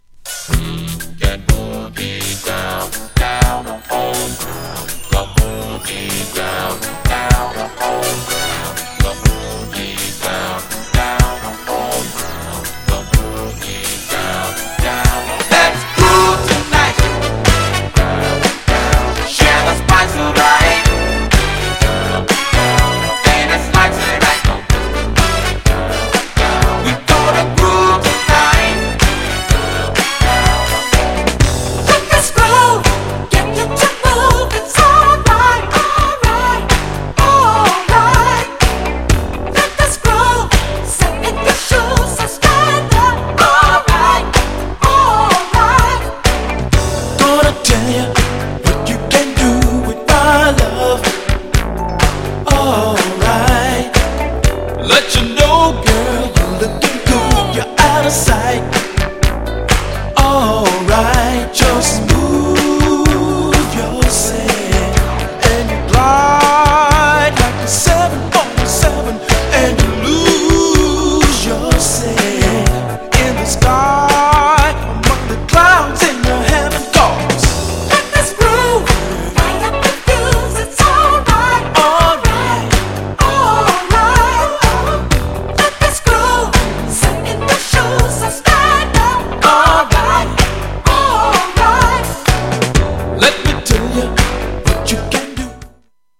GENRE Dance Classic
BPM 71〜75BPM